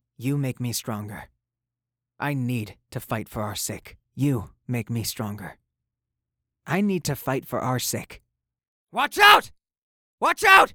Voice: Mid-high, carefree